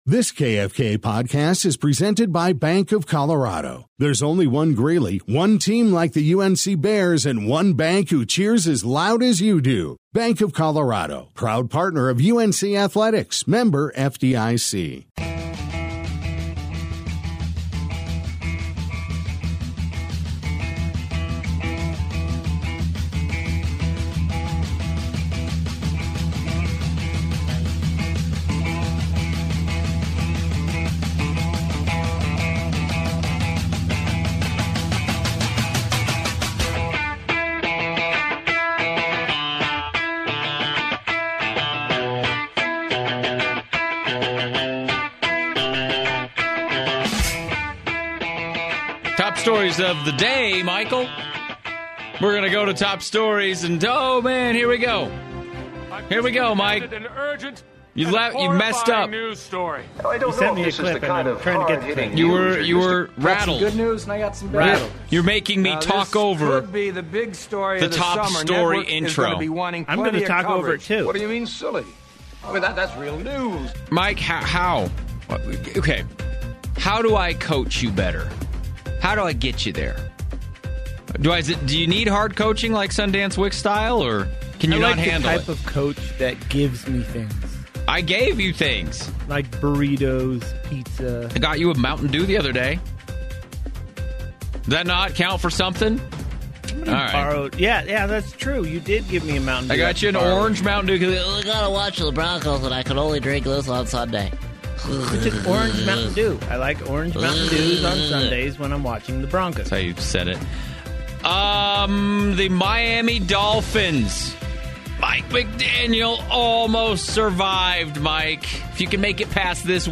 powered by Chevron is Northern Colorados true sports talk show for the well rounded sports fan.